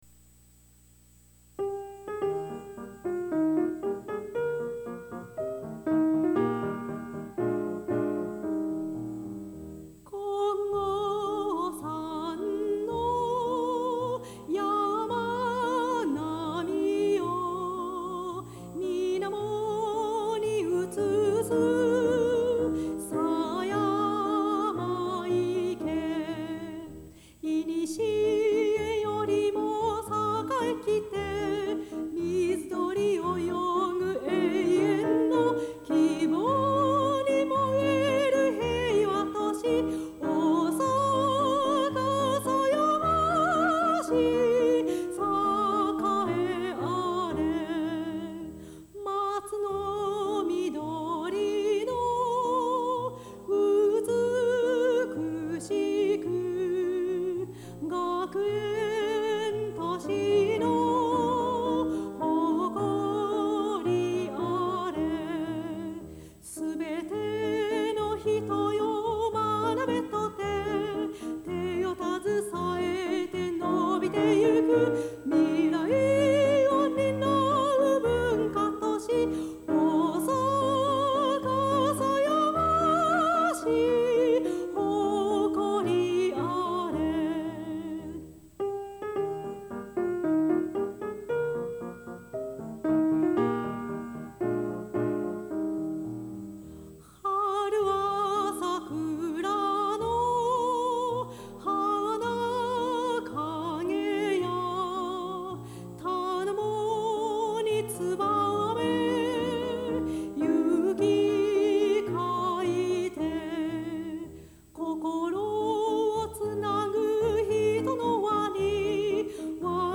独唱